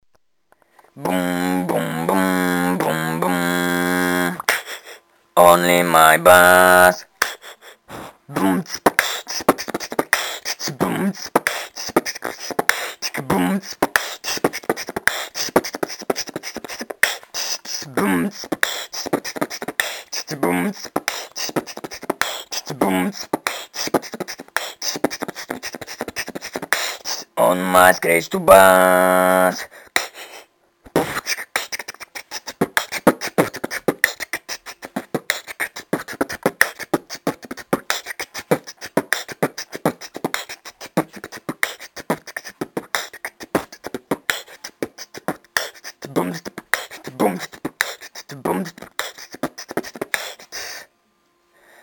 Батл новичков. Битбокс от 1 до 2 месяцев!
Делаю робот войс в моей записи вы его услышите =) качество самой записи плохое - микрофон сломался - записывал на цифровик. Бит полностью фристайл, сочинял всё на ходу! cool